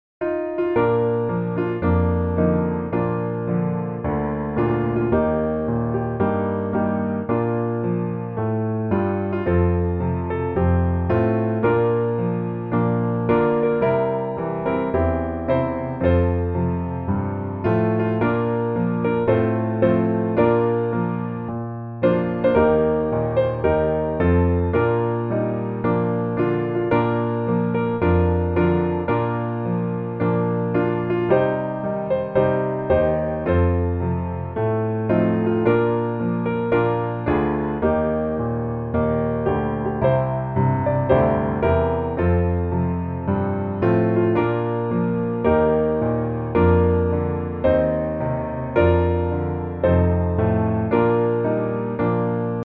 Bb Major